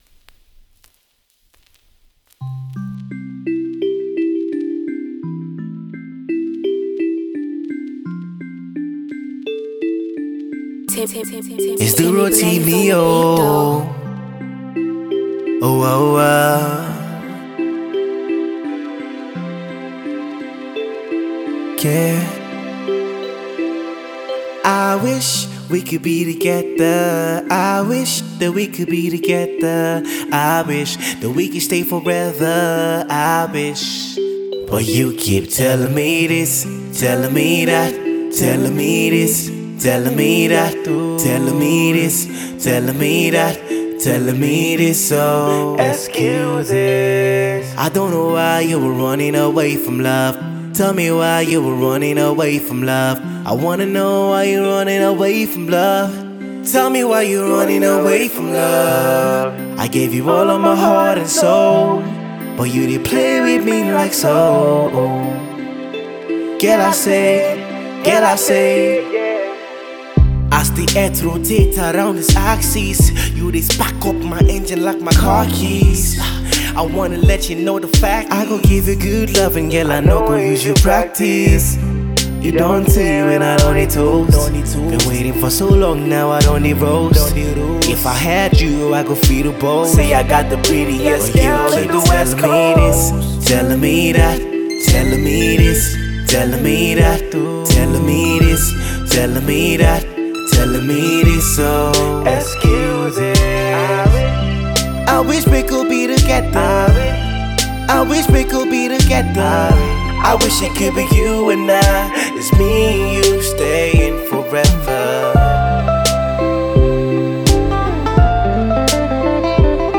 A Love Ballad